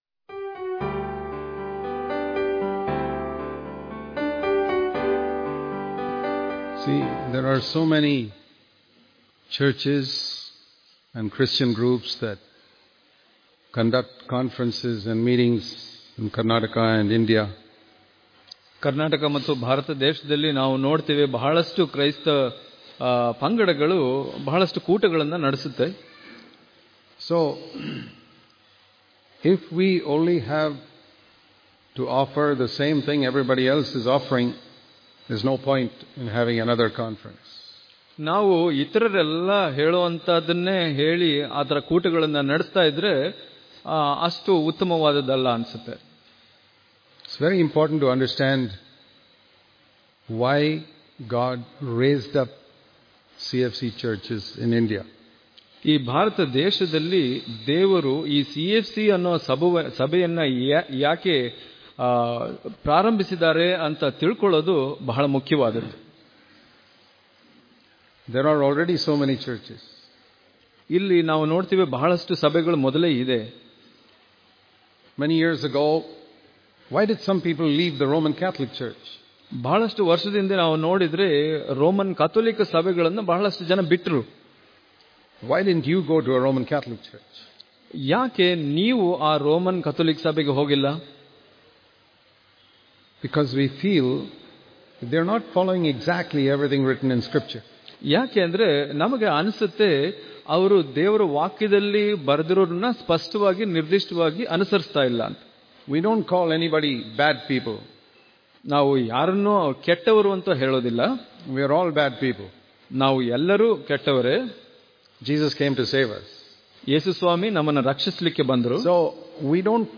December 1 | Kannada Daily Devotion | Where True Holiness Is, The World’s Darkness Cannot Cover It Daily Devotions